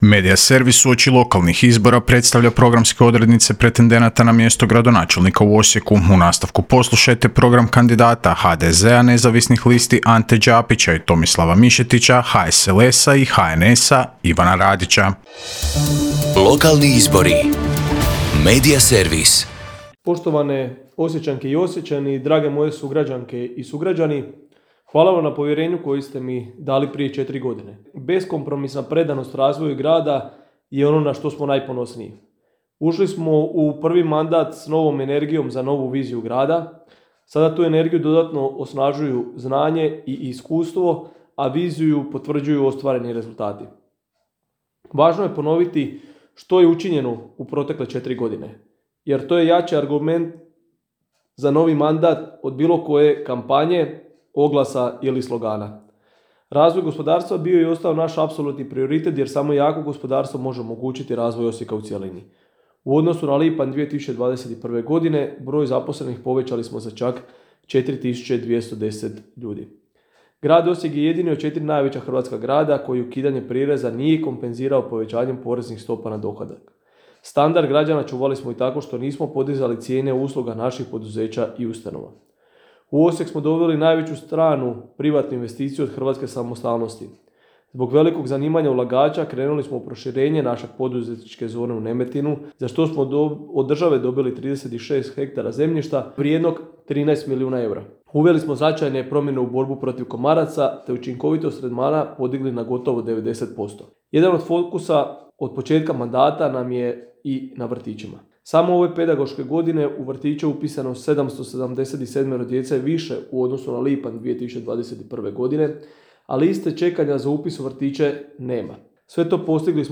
OSIJEK - Uoči lokalnih izbora kandidati za gradonačelnicu/gradonačelnika predstavljaju na Media servisu svoje programe u trajanju od 5 minuta. Kandidat HDZ-a i partnera za gradonačelnika Osijeka Ivan Radić predstavio je građanima svoj program koji prenosimo u nastavku.